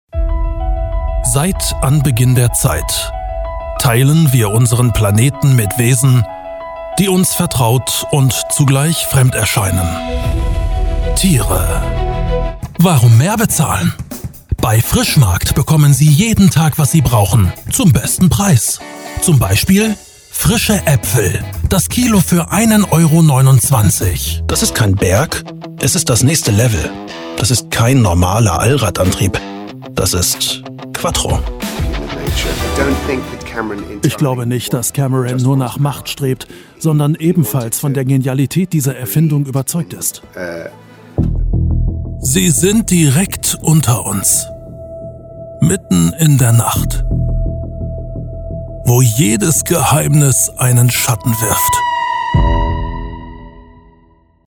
Doku, Commercial (Werbung), Comment (Kommentar)
dunkel, sonor, souverän, markant, sehr variabel
Mittel minus (25-45)
Ruhrgebiet, Norddeutsch